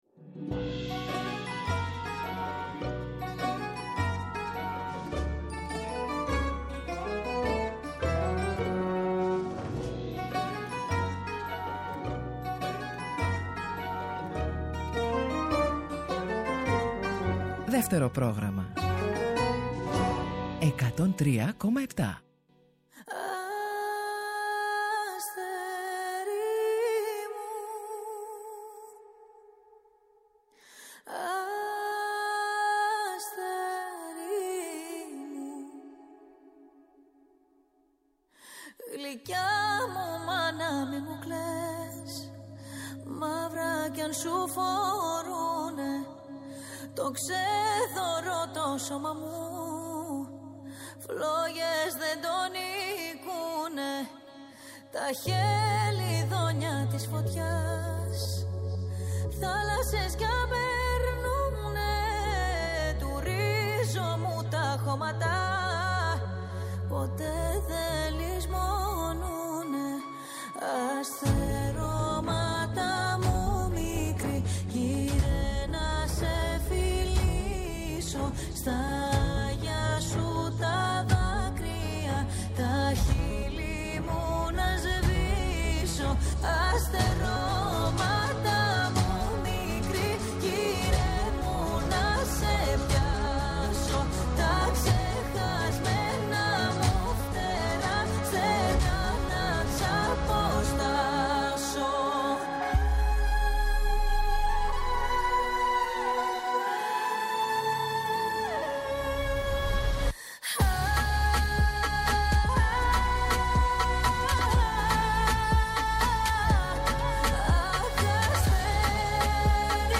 Η συνέντευξη λαμβάνει μέρος στο lobby του Μουσείου Σύγχρονης Τέχνης του Ιδρύματος Βασίλη & Ελίζας Γουλανδρή, στο Παγκράτι, σε έναν χώρο τέχνης και πολιτισμού. Η Klavdia μιλάει για τη συμμετοχή της στη Eurovision, τη μουσική της διαδρομή, τις προσδοκίες και τα συναισθήματά της, λίγο πριν ανέβει στη σκηνή της μεγαλύτερης μουσικής διοργάνωσης της Ευρώπης. Επιπλέον, θα επιλέξει τα αγαπημένα της ελληνικά τραγούδια που την επηρέασαν στη μουσική της διαδρομή, δημιουργώντας ένα ξεχωριστό ραδιοφωνικό ταξίδι.